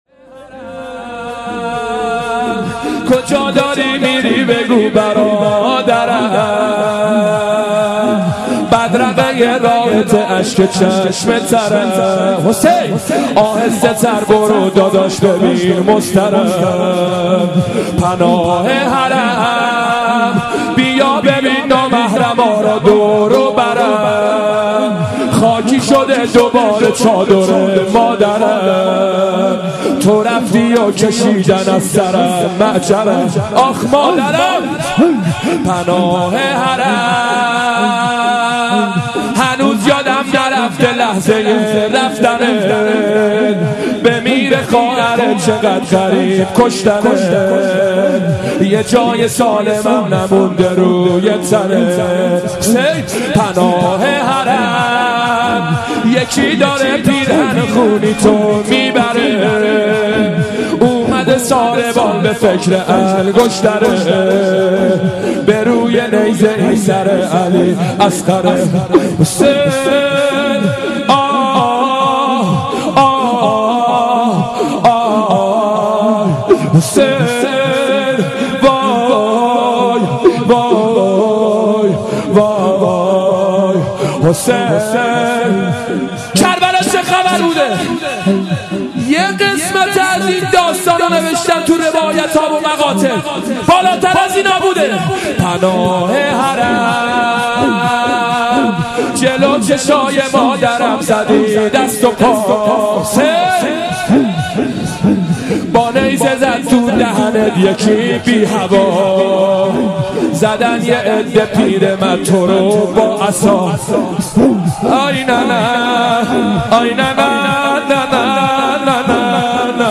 شب هفتم رمضان95، حاج محمدرضا طاهری
زمینه، روضه، مناجات